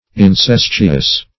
Incesttuous \In*cest"tu*ous\ (?; 135), a. [L. incestuosus: cf.